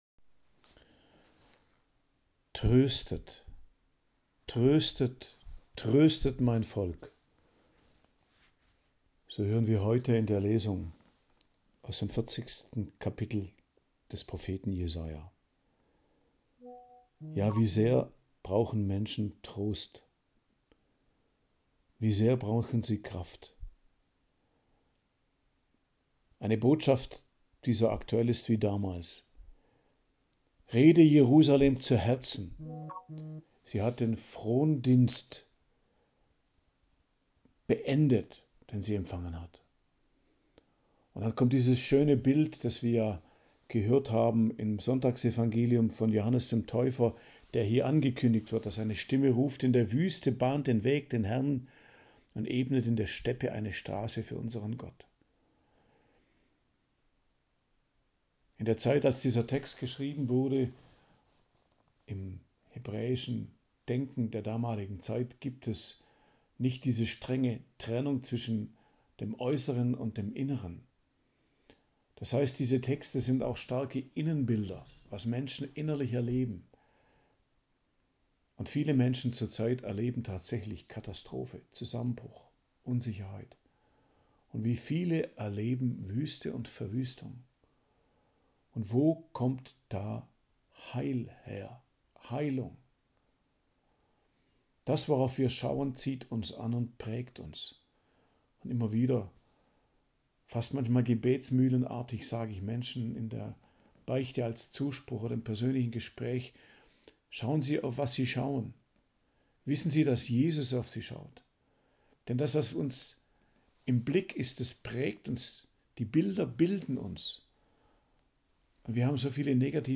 Predigt am Dienstag der 2. Woche im Advent, 10.12.2024